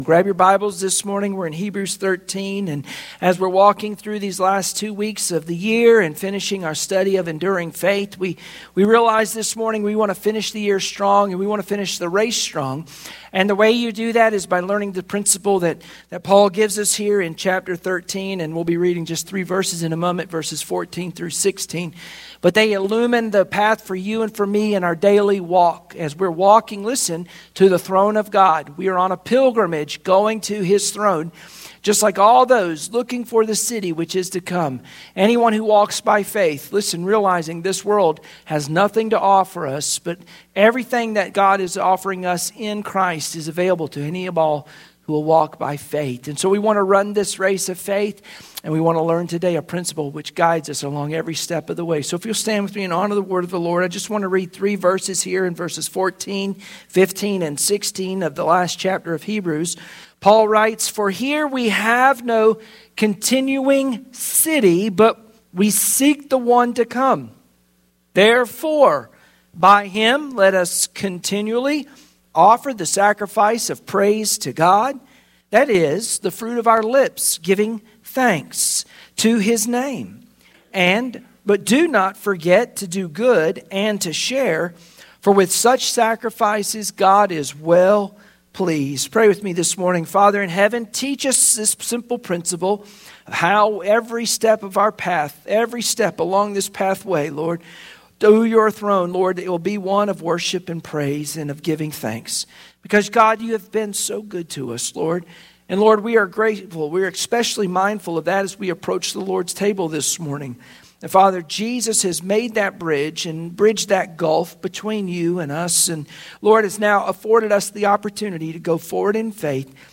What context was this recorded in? Hebrews 13:14-16 Service Type: Sunday Morning Worship Share this